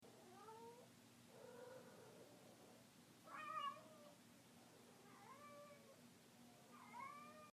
Field Recording #5 – Cats
It’s so different from a normal meow. It’s deep and desperate and NONSTOP.